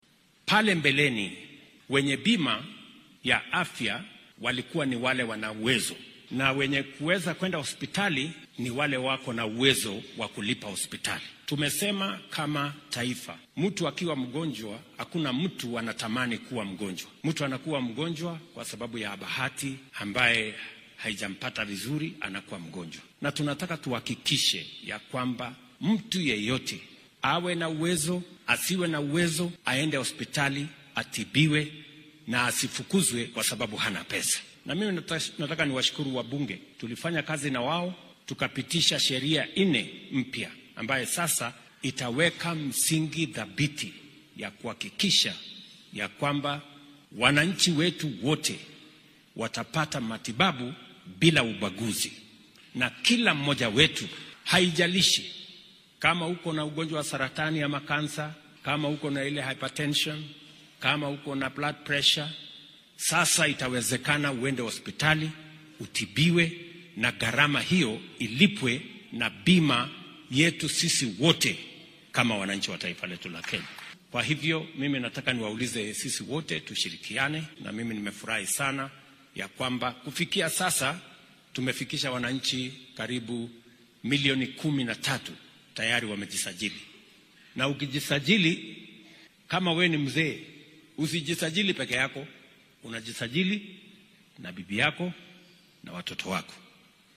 Xilli uu ismaamulka Uasin Gishu uga qayb galay munaasabad kaniiseed ayuu William Ruto madaxda ku boorriyay in ay xaqiijiyaan ka shaqeynta midnimada kenyaanka.